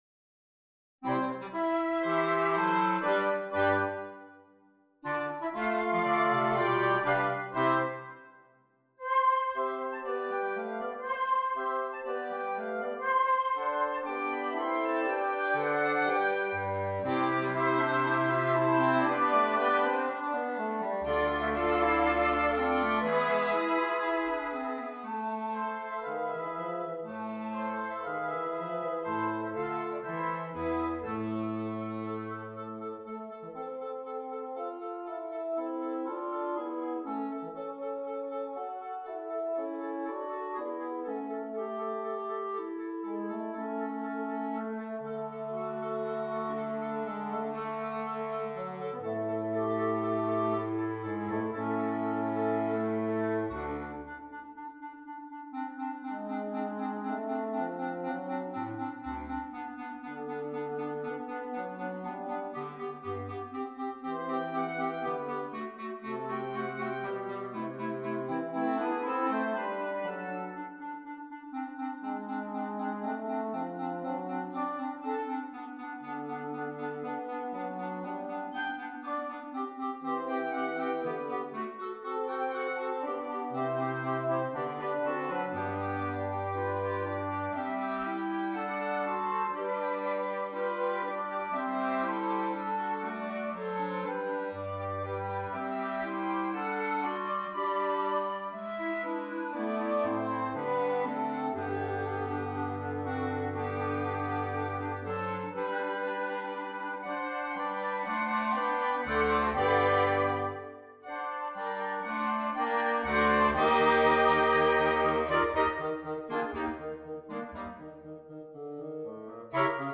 2 Flutes, Oboe, Bassoon, 2 Clarinets, Bass Clarinet